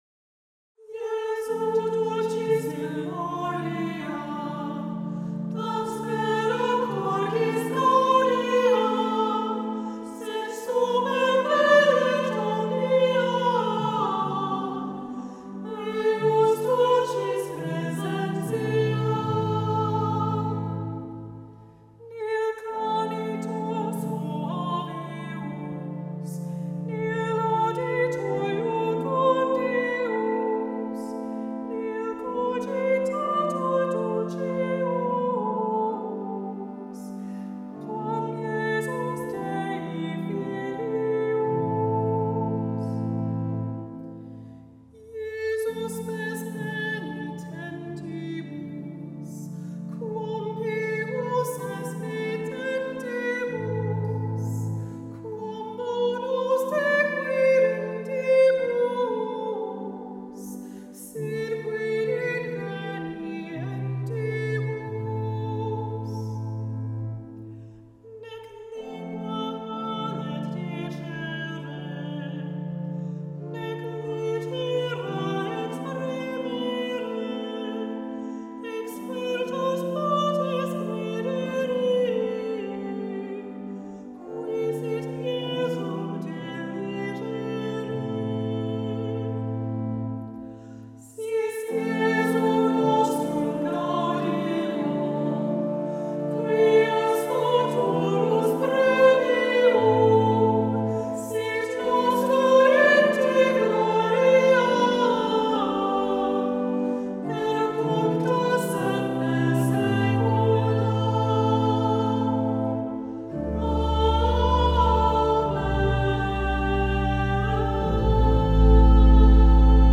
2018: St. Joseph Church, Needham
7. Chant: Jesu dulcis memoria
Choir: